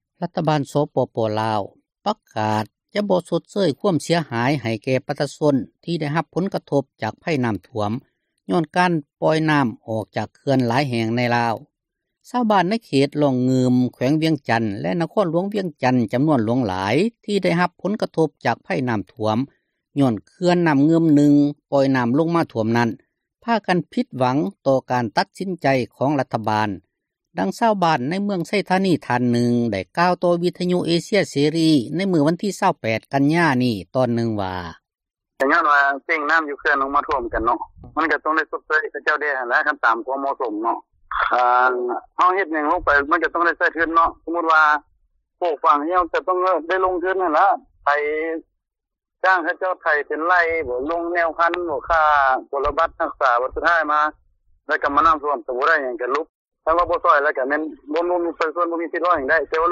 ຊາວບ້ານ ອີກທ່ານນຶ່ງ ໄດ້ກ່າວ ໃນມື້ດຽວກັນນັ້ນວ່າ ເຖິງທາງການ ບໍ່ມີມາຕການ ຊ່ອຍເຫຼືອເປັນ ເງິນຊົດເຊີຍໃຫ້, ກໍຢາກຮຽກຮ້ອງ ໃຫ້ຊ່ອຍເຫຼືອ ເຣື່ອງແນວພັນເຂົ້າປູກ ແລະ ມີນະໂຍບາຍ ໃຫ້ແກ່ ຊາວນາຊາວສວນ ໃນການກູ້ຢືມເງິນ ຜ່ານກອງທຶນບ້ານ ເພາະເງິນຄໍາ ທີ່ກູ້ຢືມມາ ປູກພືດພັນ ໃນປີນີ້ ກໍເສັຽຫາຍ ໄປກັບນ້ຳຖ້ວມແລ້ວ: